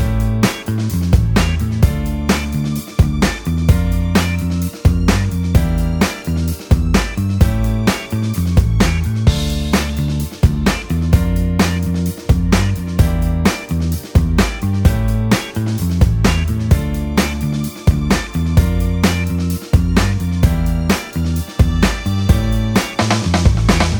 no Backing Vocals R'n'B / Hip Hop 3:37 Buy £1.50